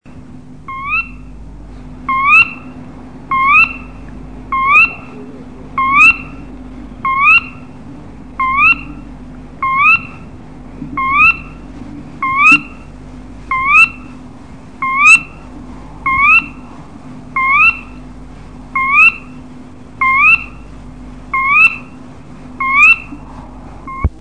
Karolinasumphöna i Aneby 1966 - Ett 40-årsminne
Jag var i ett våtmarksområde vid Ralångens sydspets, strax norr om Aneby i Småland.
Rytmen hos lätet påminde om den småfläckiga sumphönans spel medan tonhöjden mer liknade en kattugglas klävitt. Detta karaktäristiska ki-iip,med synthersizerton och med andra stavelsen stigande hördes sedan varje kväll ifrån nio-tiden fram till tre-tiden på morgonen till och med den 17 juni.
Vi spelade in ljudet den 14 juni för en eventuell senare identifikation.